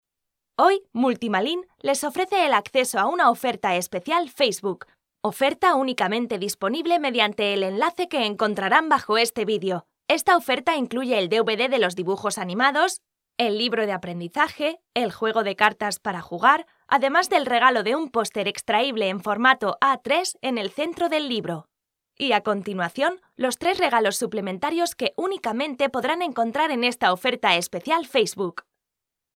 6 西班牙语女4_外语_小语种_0.5aes 西班牙语女4
西班牙语女4_外语_小语种_0.5aes.mp3